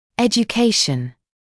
[ˌeʤu’keɪʃn][ˌэджу’кейшн]образование; обучение; просвещение, информирование